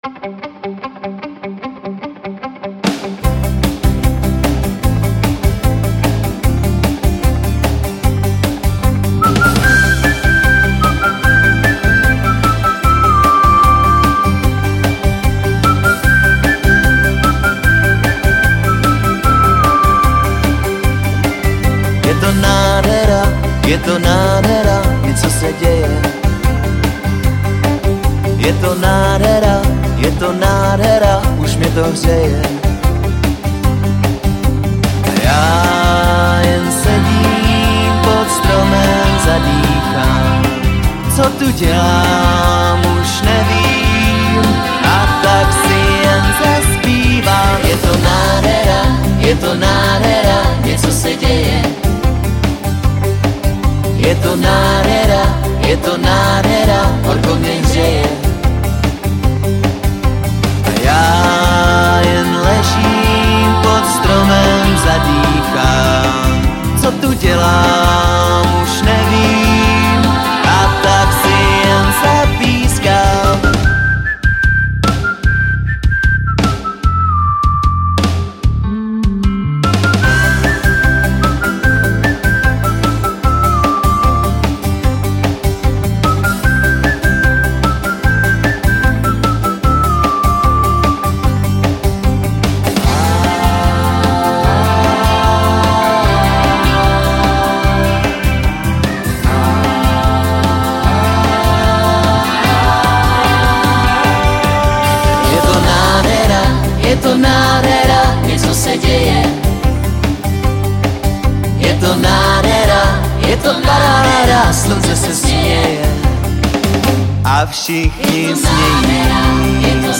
Žánr: Pop
veselý nekomplikovaný pop rock